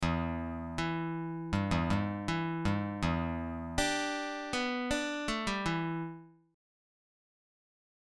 Blues lick > lick 7